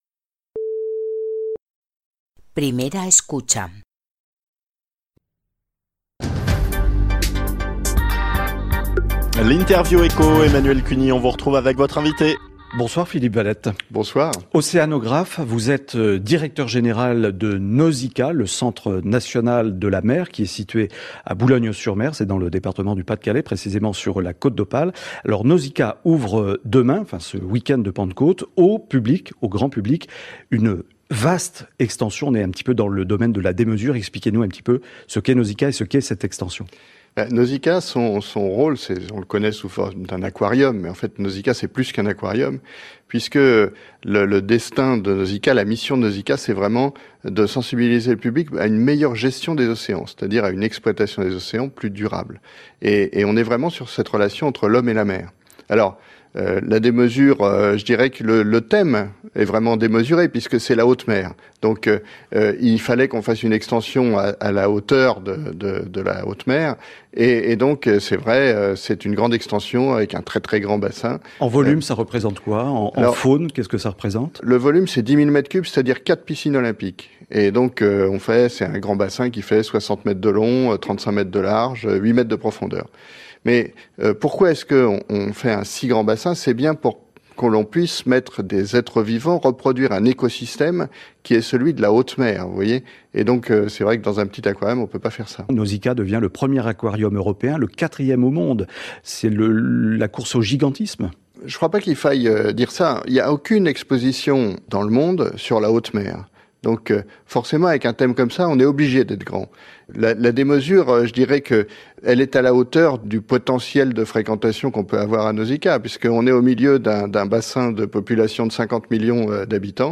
TAREA 2 - 10 puntos: Vous allez écouter trois fois un extrait d'une émission de France info.